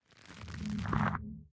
Minecraft Version Minecraft Version 1.21.5 Latest Release | Latest Snapshot 1.21.5 / assets / minecraft / sounds / block / chorus_flower / grow3.ogg Compare With Compare With Latest Release | Latest Snapshot
grow3.ogg